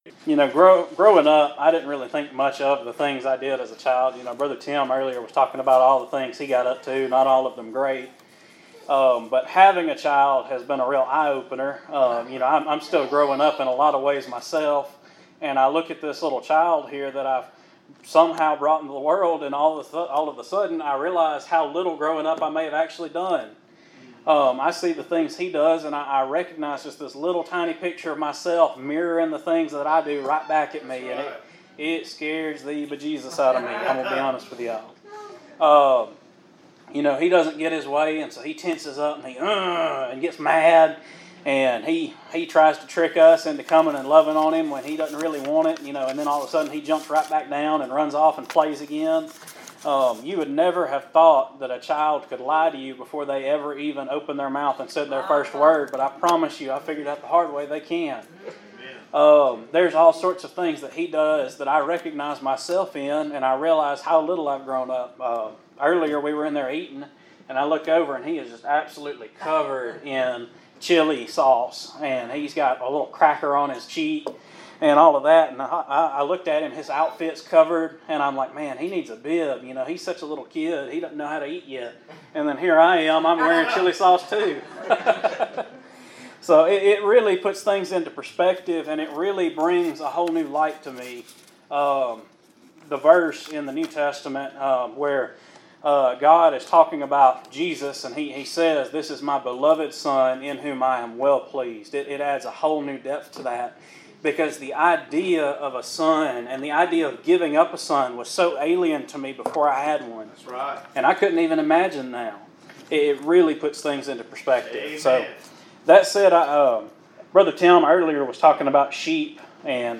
2025 Fellowship Meeting.